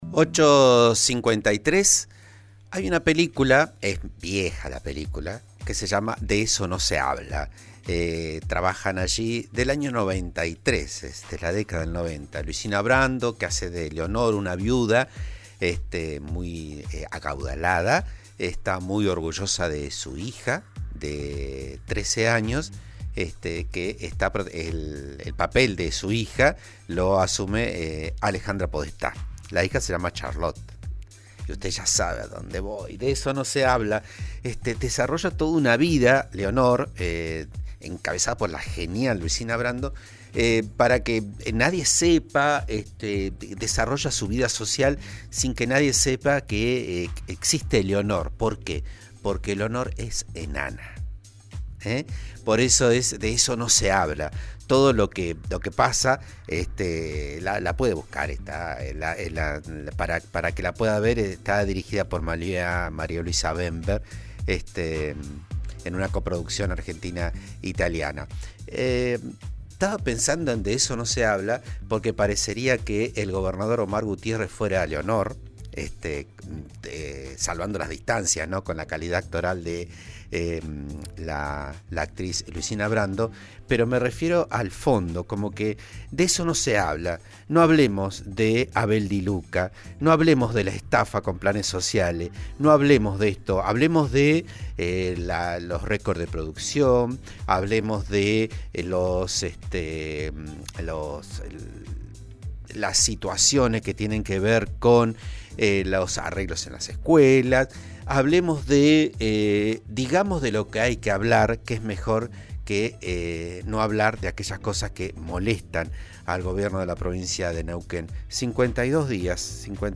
Análisis: la estafa con planes sociales en Neuquén, «De eso no se habla» y Rolando Figueroa como enemigo